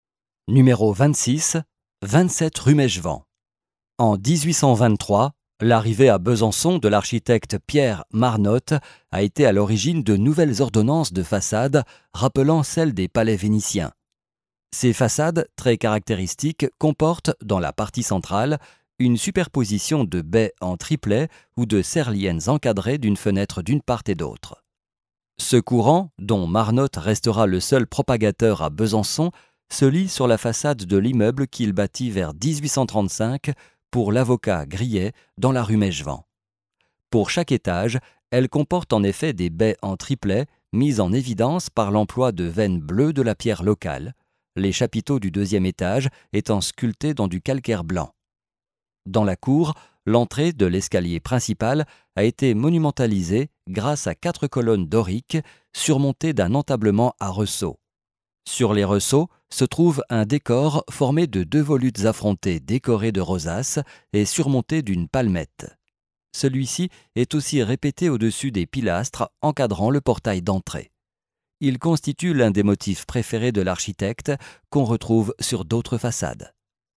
Ecouter l'audio guide